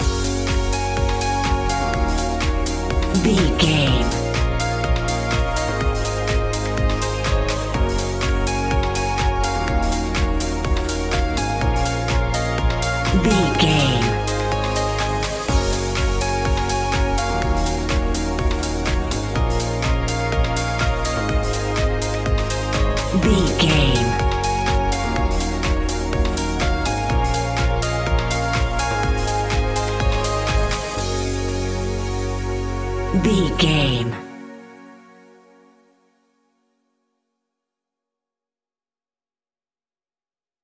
Aeolian/Minor
B♭
groovy
hypnotic
uplifting
drum machine
synthesiser
house
electro house
synth bass